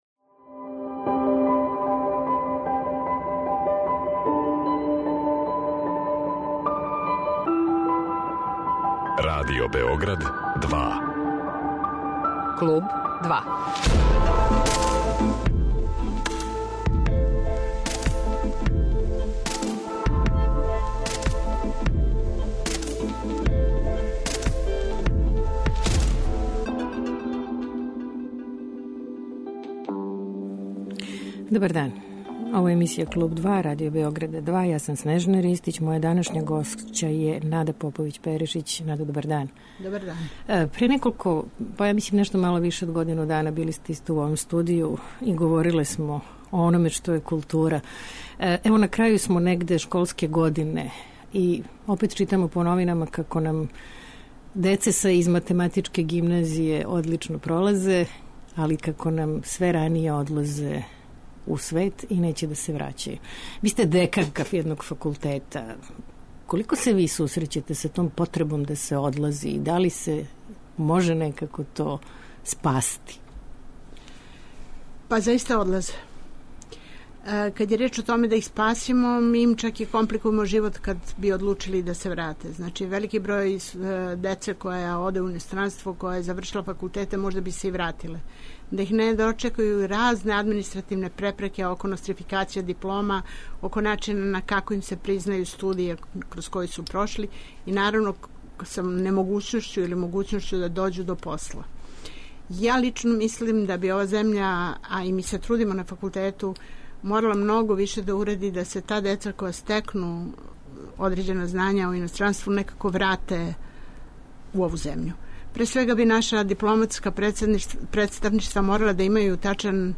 Гошћа: Нада Поповић Перишић